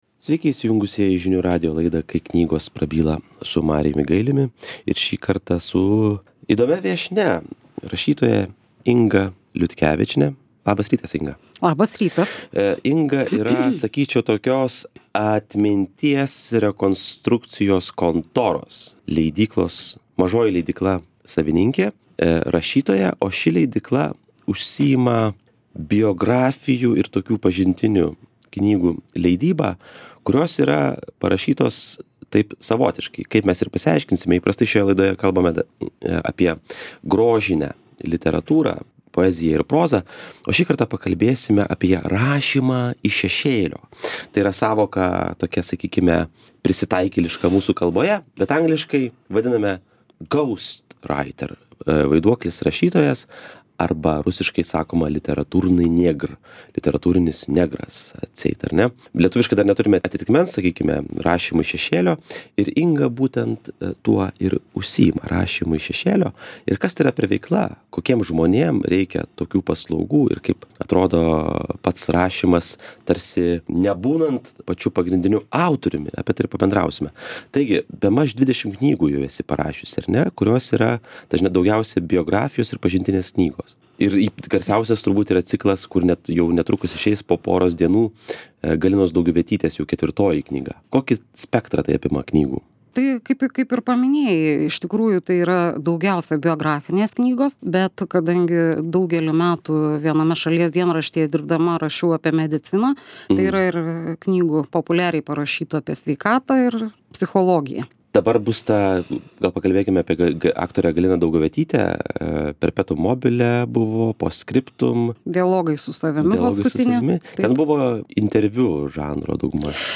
Pasakoja rašytoja